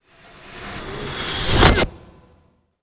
magic_conjure_charge2_04.wav